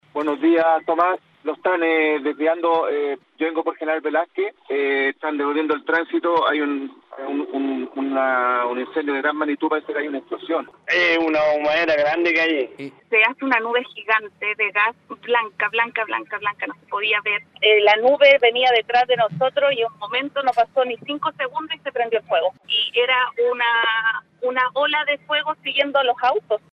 Todo comenzó durante la mañana, a primera hora, cuando auditores de La Radio dieron aviso de la emergencia que se estaba desarrollando.